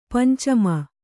♪ panca ma